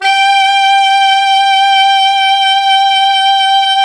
Index of /90_sSampleCDs/Roland LCDP07 Super Sax/SAX_Sax Ensemble/SAX_Sax Sect Ens
SAX 2 ALTO0B.wav